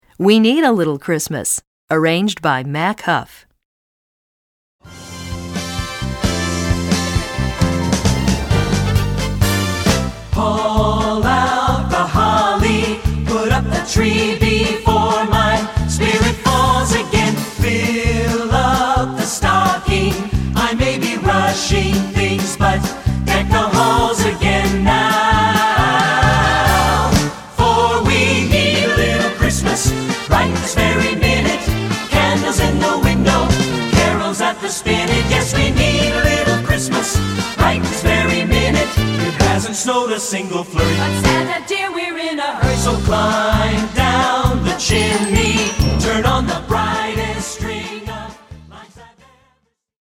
Feuillet pour Chant/vocal/choeur - Voix Mixtes